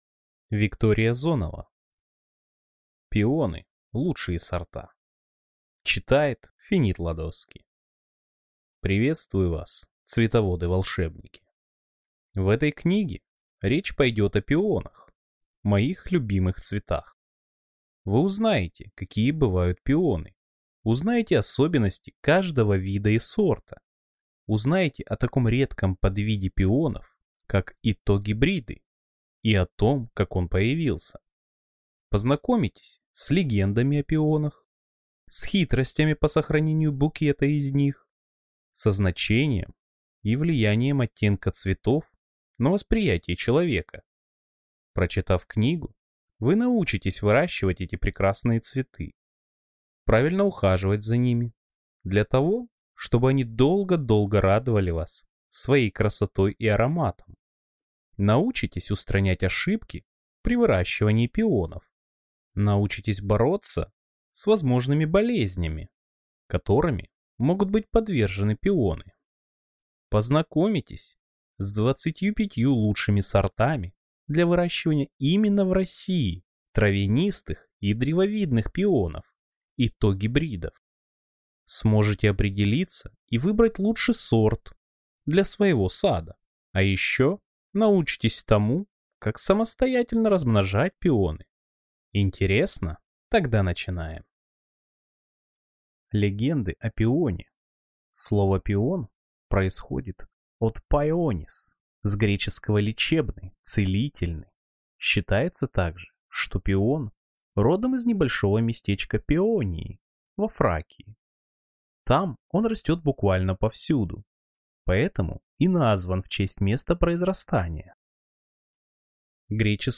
Аудиокнига Пионы. Лучшие сорта | Библиотека аудиокниг